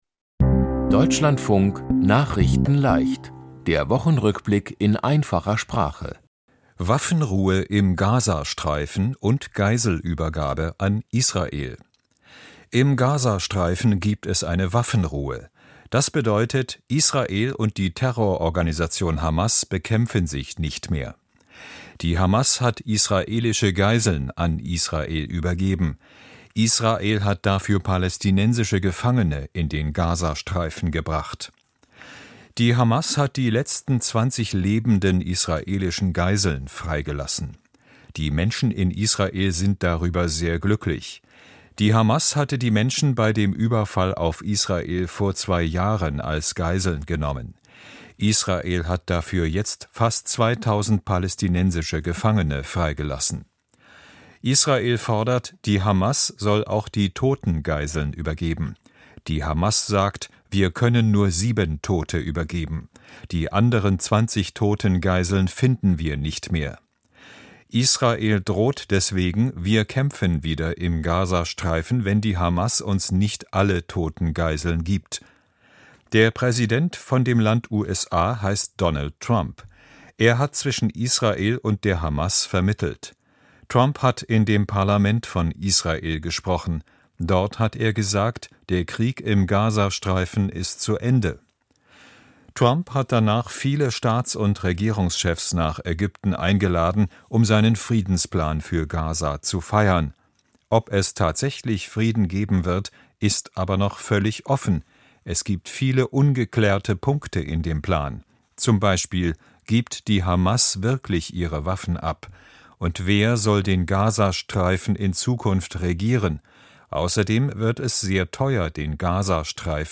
Der Wochen-Rückblick in Einfacher Sprache